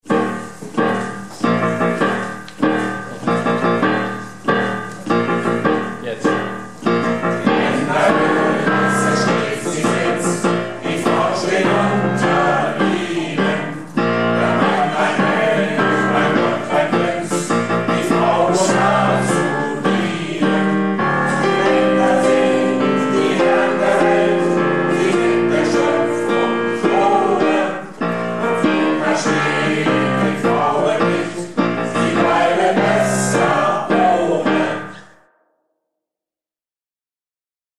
Projektchor "Keine Wahl ist keine Wahl" - Probe am 11.09.19